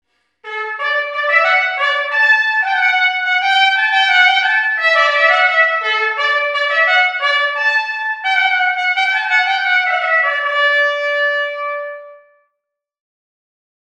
performing_convolution_auralized_sound.wav